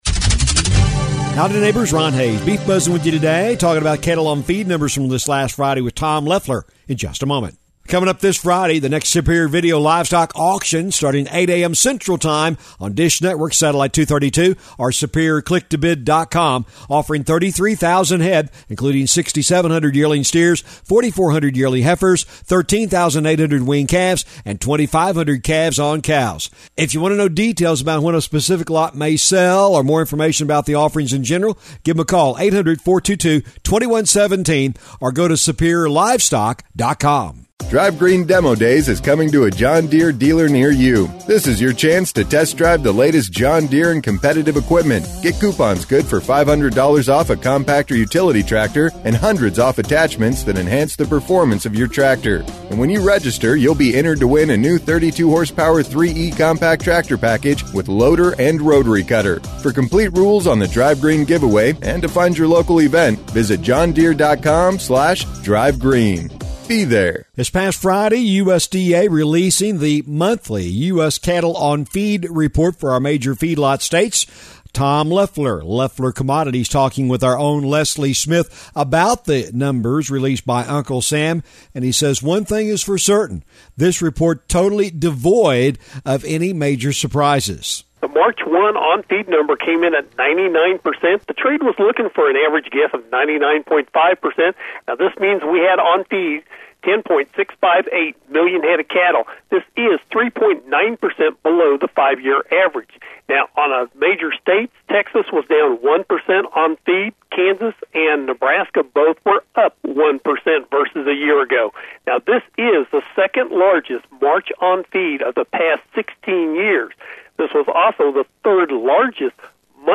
The Beef Buzz is a regular feature heard on radio stations around the region on the Radio Oklahoma Network- but is also a regular audio feature found on this website as well.